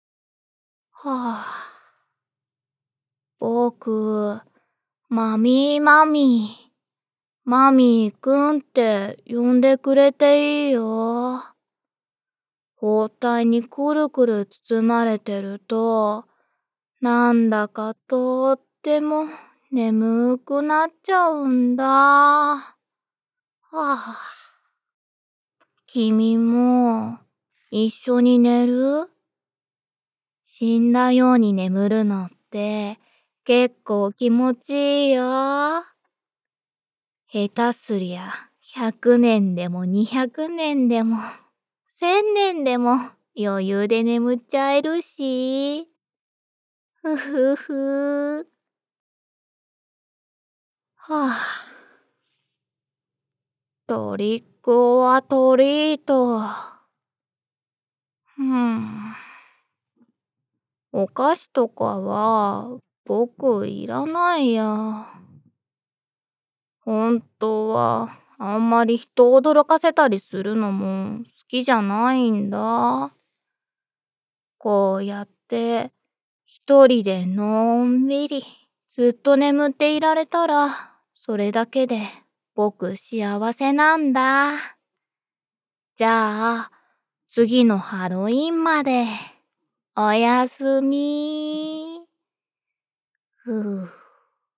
マミーの少年。
いつも眠そうに間延びした話し方をする